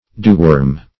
Dewworm \Dew"worm`\, n. (Zool.)